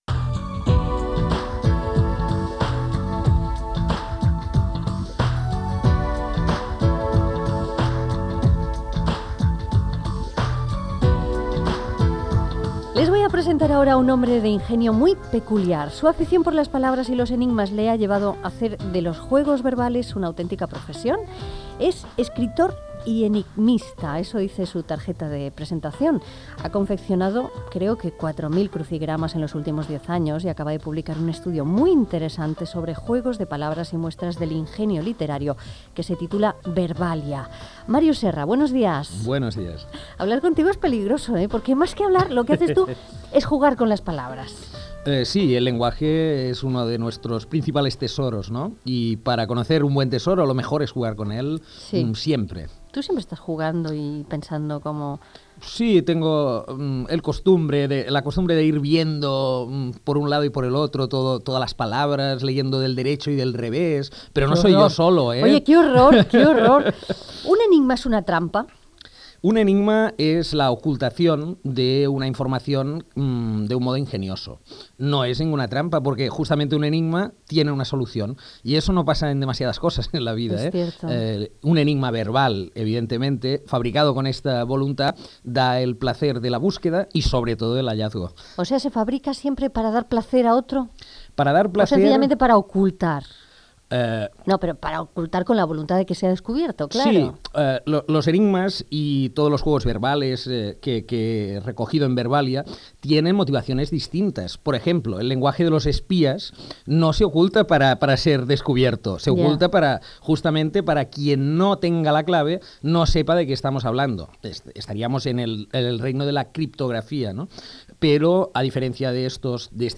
Entrevista a l'escriptor Màrius Serra, que ha publicat el llibre "Verbàlia", sobre els jocs de paraules
Entreteniment